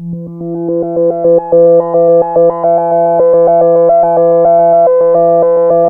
JUP 8 E4 11.wav